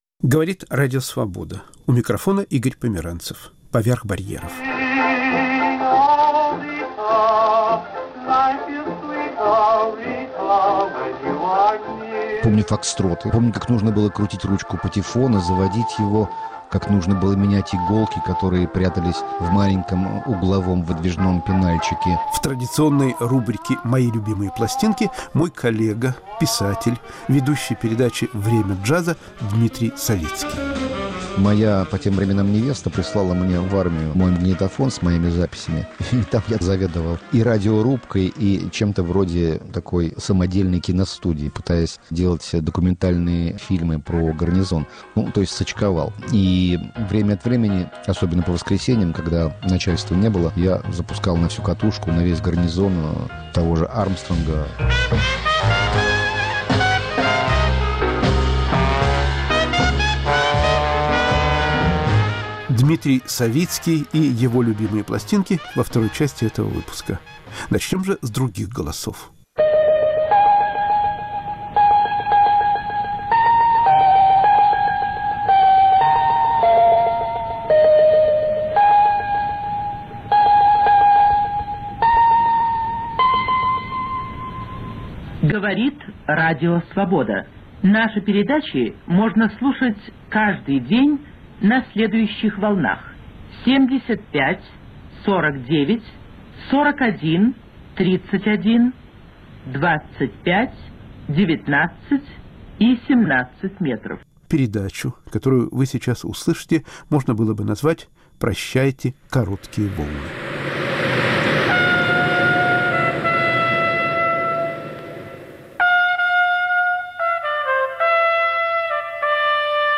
Прощайте, короткие волны. Архивные записи, голоса ветеранов и слушателей «Свободы».